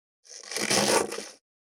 531厨房,台所,野菜切る,咀嚼音,ナイフ,調理音,まな板の上,料理,
効果音厨房/台所/レストラン/kitchen食器食材